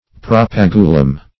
Search Result for " propagulum" : The Collaborative International Dictionary of English v.0.48: Propagulum \Pro*pag"u*lum\, n.; pl. Propagula .
propagulum.mp3